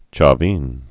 (chä-vēn)